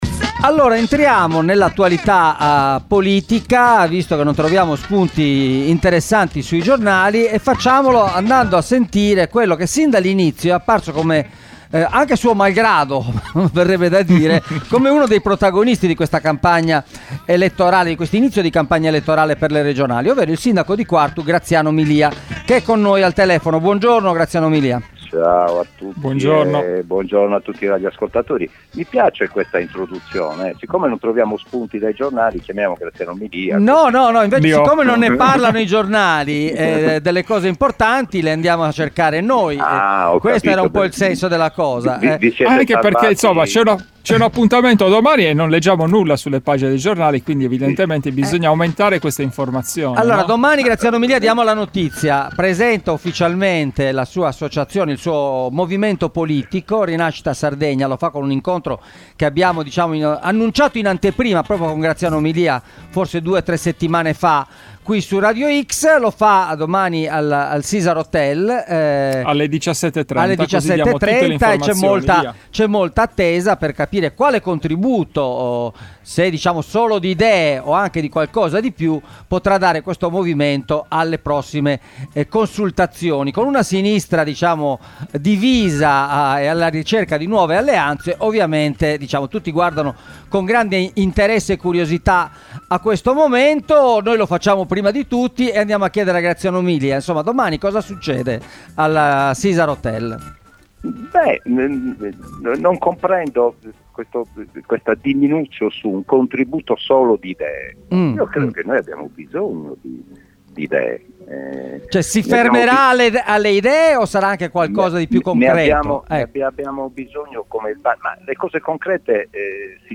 A poche ore dal lancio ufficiale del suo nuovo movimento, Rinascita Sardegna, il sindaco di Quartu Graziano Milia è intervenuto ai microfoni di Radio X per raccontare le ragioni di un lungo lavoro dedicato all’elaborazione programmatica e alla costruzione di un progetto politico che guarda all’intera Isola.